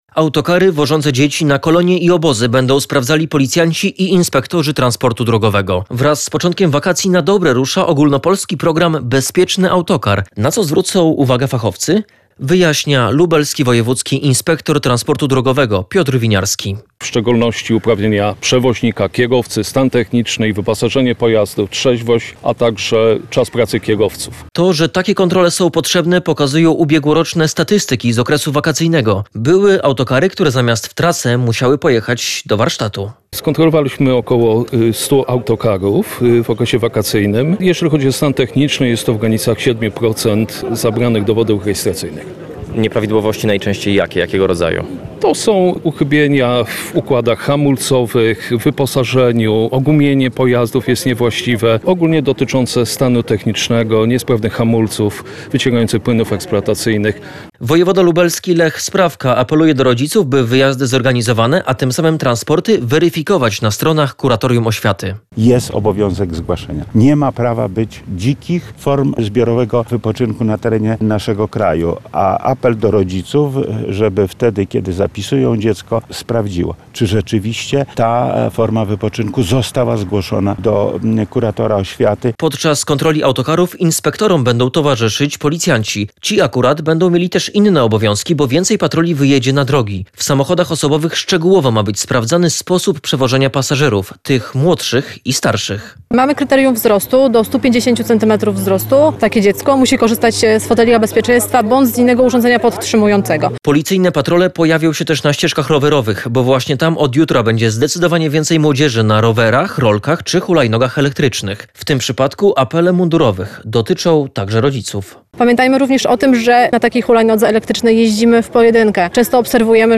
Briefing prasowy nad Zalewem Zemborzyckim Policyjne kontrole czekają kierowców, rowerzystów i użytkowników hulajnóg Podczas kontroli autokarów inspektorom będą towarzyszyć policjanci.